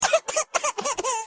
Crying Sound Effect Download: Instant Soundboard Button